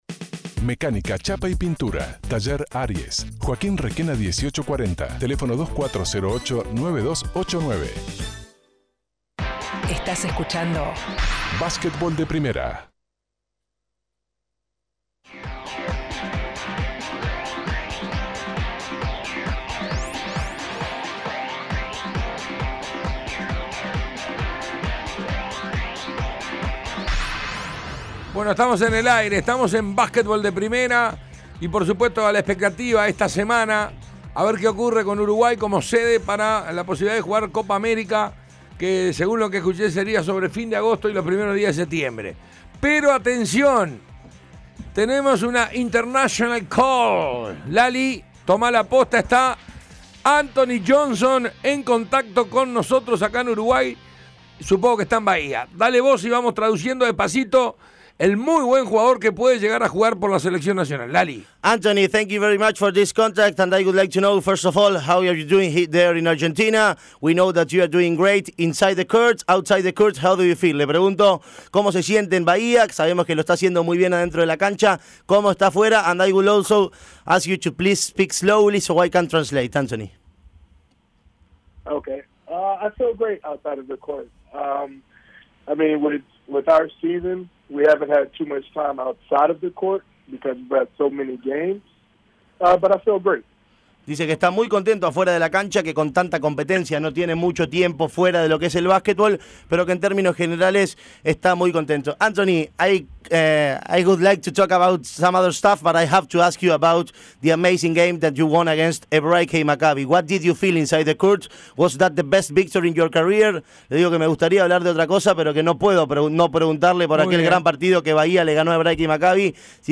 También analiza su presente en Weber Bahía. Entrevista completa, imperdible.